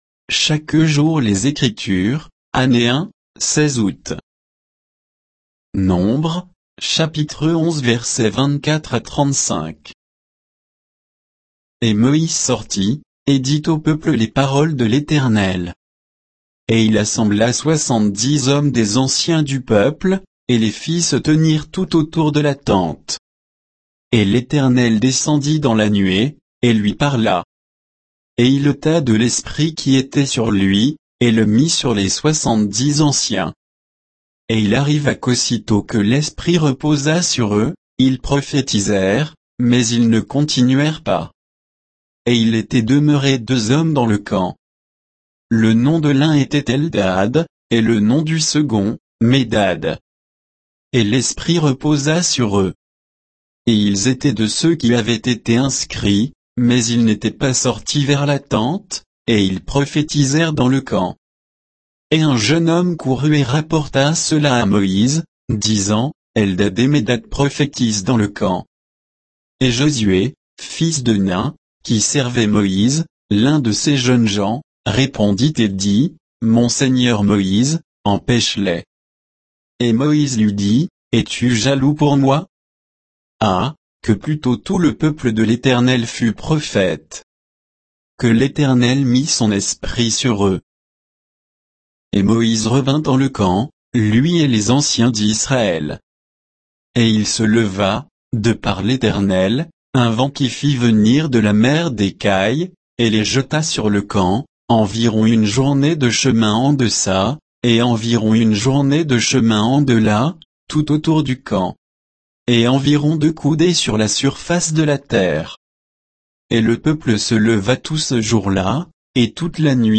Méditation quoditienne de Chaque jour les Écritures sur Nombres 11